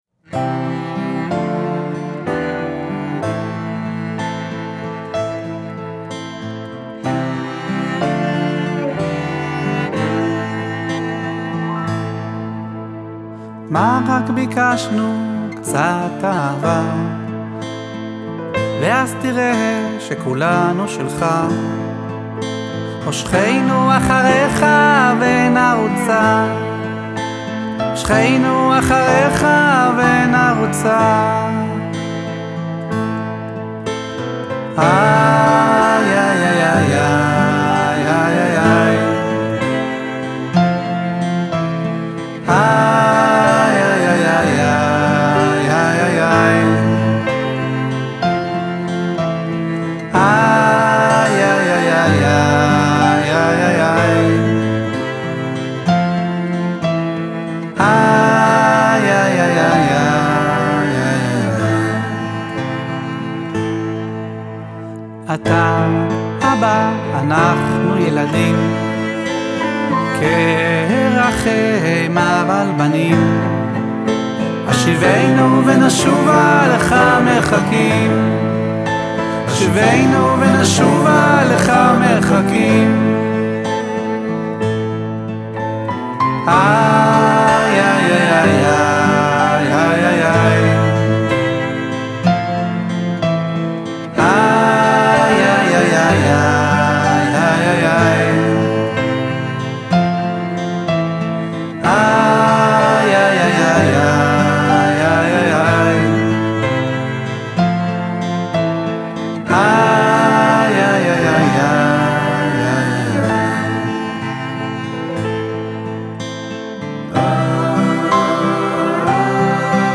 באותו לילה בשעת ליל מאוחרת תפסתי את הגיטרה והשיר הגיע